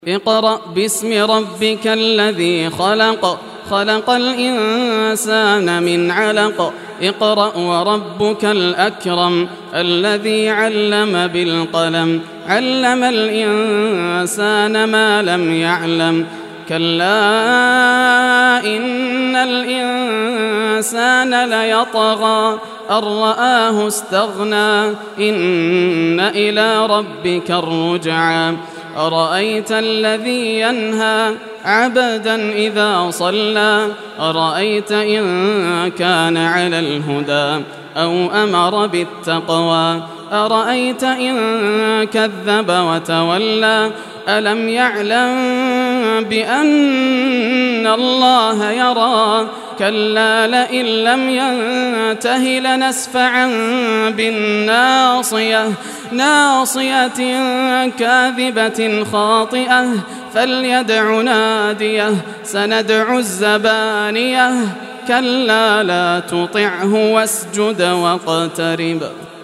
Surah Al-Alaq Recitation by Yasser al Dosari
Surah Al-Alaq, listen or play online mp3 tilawat / recitation in Arabic in the beautiful voice of Sheikh Yasser al Dosari.